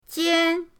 jian1.mp3